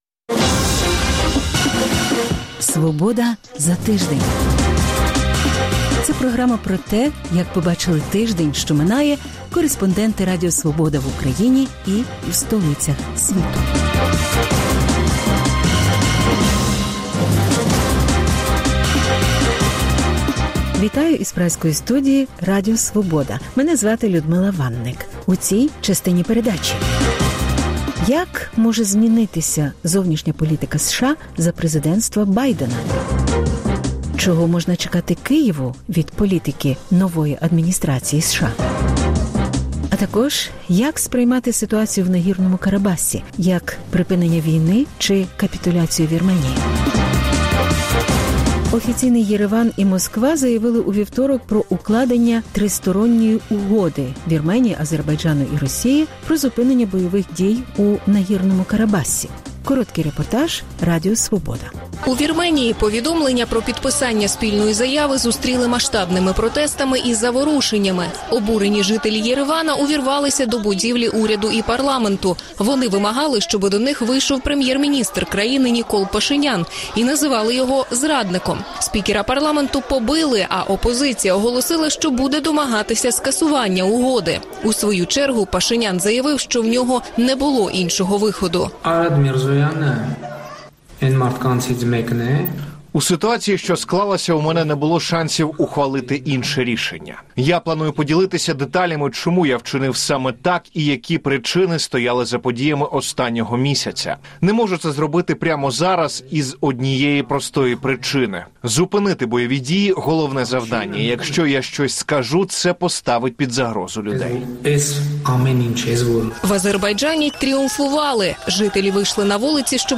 Репортаж із батьківщини першої українки, яку обрали в Конгрес США. П’ять речей, які потрібно знати про вакцину Pfizer.